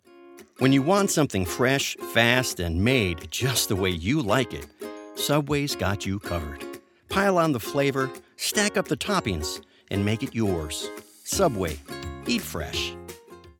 Promo Voiceover
Energetic. Confident. Built to Get Audiences Watching.
Promo Demos